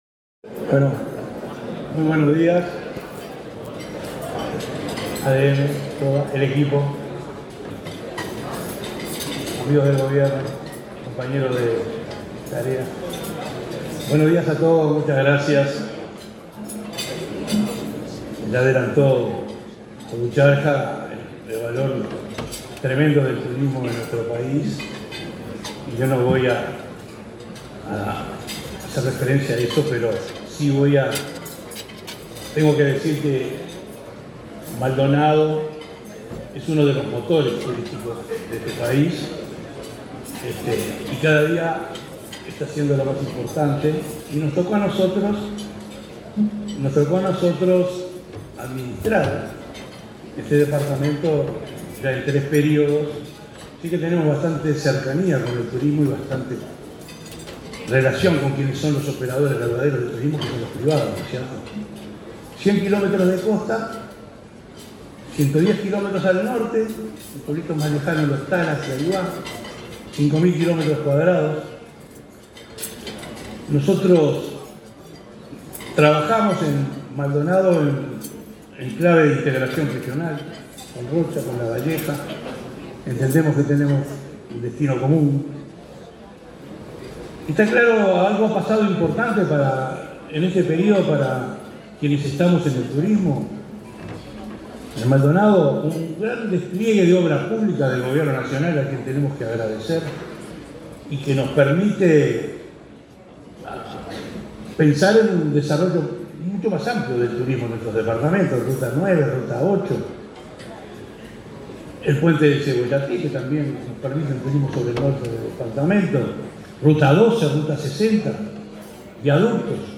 Disertación del intendente de Maldonado, Enrique Antía
El intendente de Maldonado, Enrique Antía, disertó, este jueves 7 en Montevideo, en un almuerzo de trabajo de la Asociación de Dirigentes de Marketing